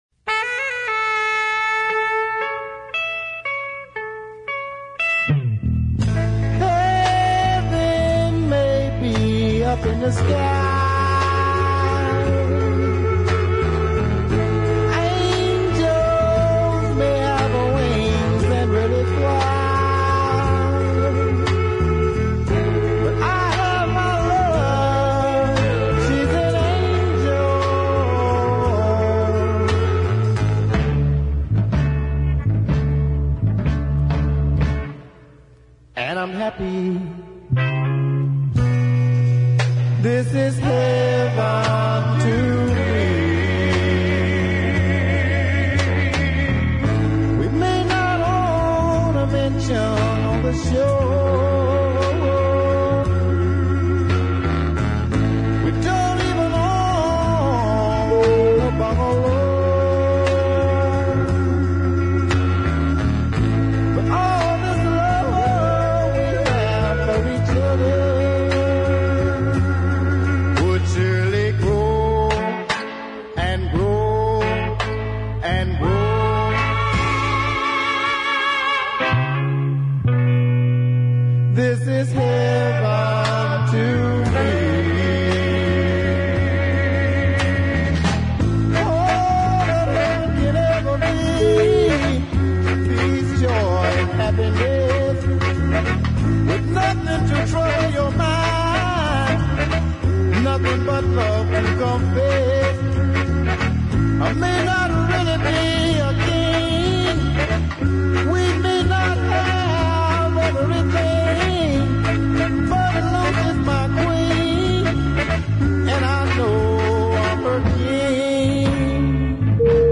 This dead slow ballad has a strong tune